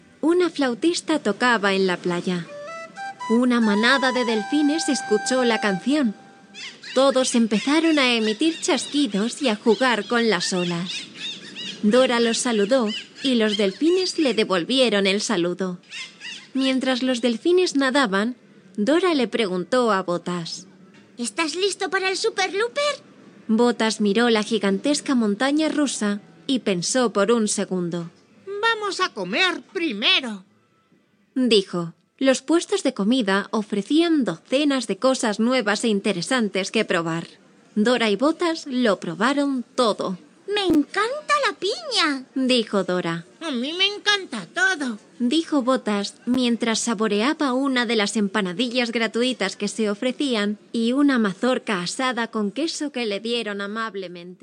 Mi voz se adapta al ritmo y estilo de cada historia, creando una narrativa cautivadora.
Fragmentos de algunas narraciones de libros: